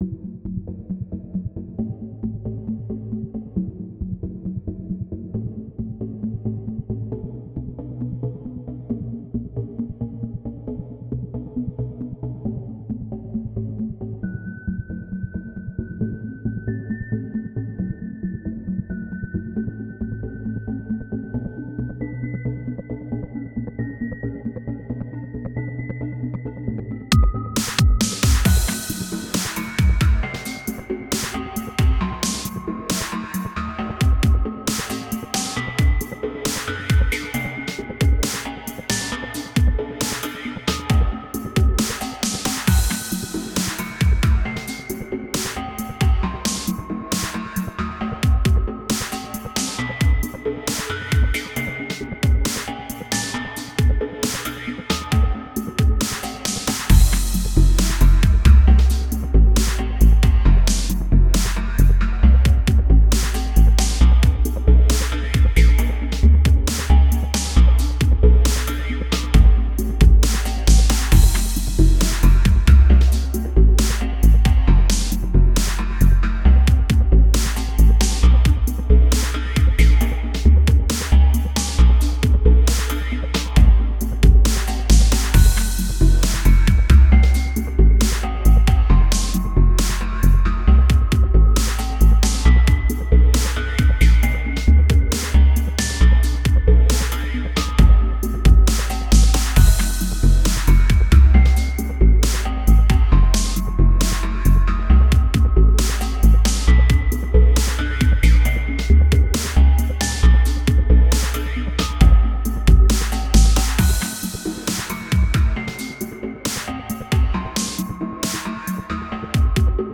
a short intro and a simple beatish loop